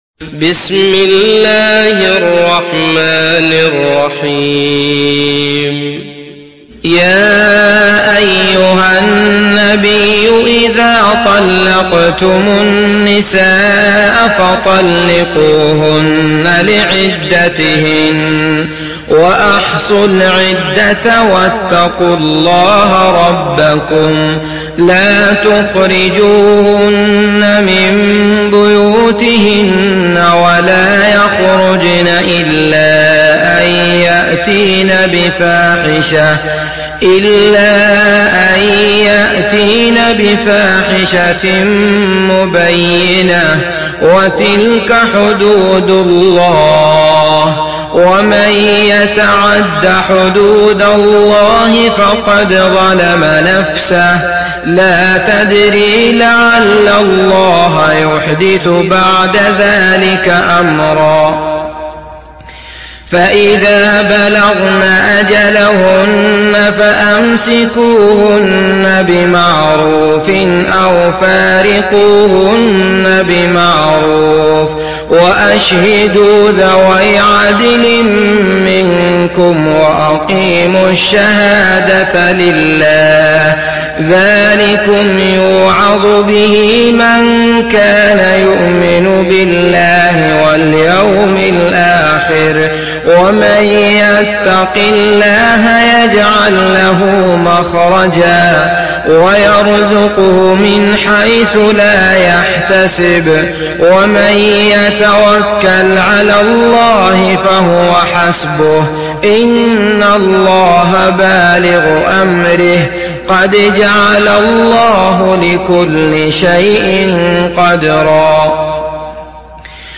Surat At Talaq Download mp3 Abdullah Al Matrood Riwayat Hafs dari Asim, Download Quran dan mendengarkan mp3 tautan langsung penuh